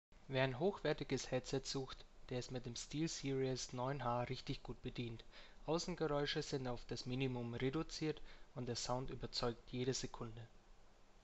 Die Stimme klingt durch die Funktion der Rauschunterdrückung sauber und die Aufnahmequalität des Mikrofons ist gut, bekommt jedoch kleine Abzüge, da es in dieser Preisklasse definitiv bessere gibt.
Hier eine kleine Hörprobe ohne Rauschunterdrückung:
Steelseries-9H-Headset-ohne-Rauschunterdrückung-2.wma